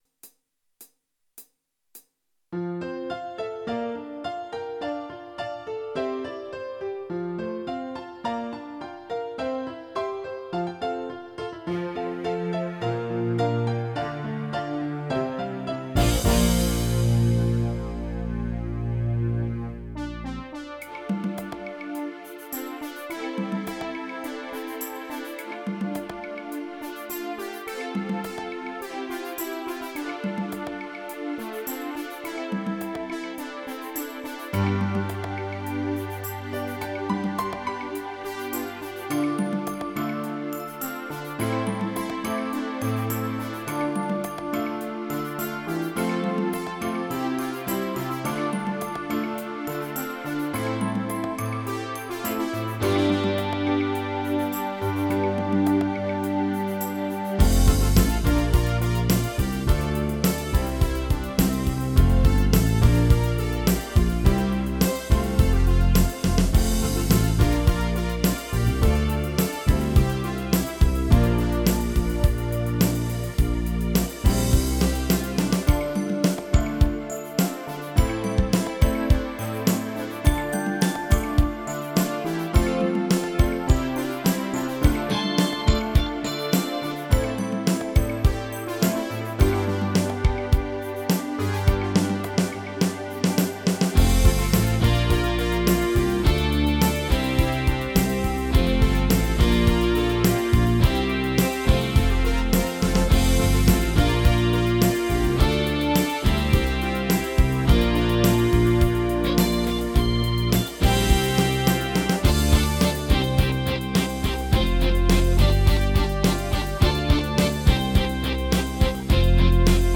Playalong mit Melodie Playalong ohne Melodie